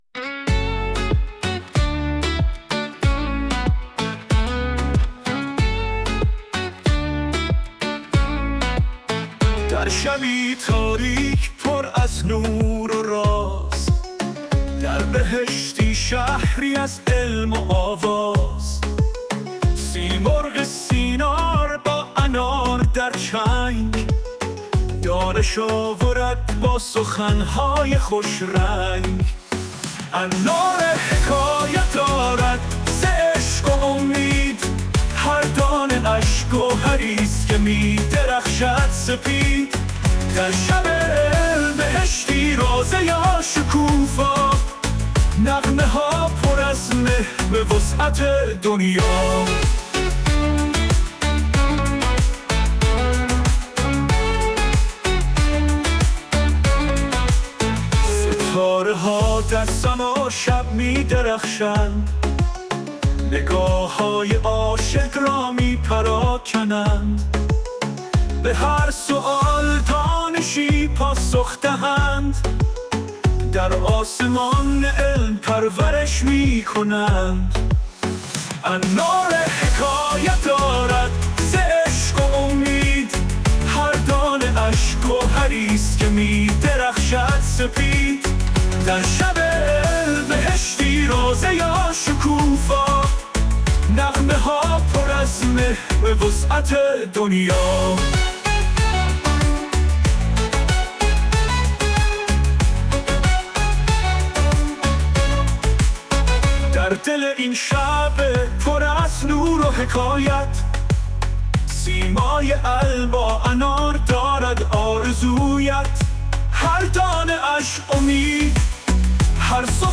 آهنگ شب علم دانشگاه شهید بهشتی ساخته‌شده با هوش مصنوعی
شبی با نوای هوش مصنوعی؛